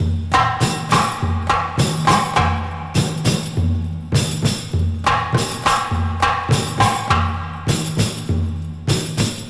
Tuned drums (F key) Free sound effects and audio clips
• 101 Bpm Drum Beat F Key.wav
Free drum groove - kick tuned to the F note. Loudest frequency: 890Hz
101-bpm-drum-beat-f-key-nar.wav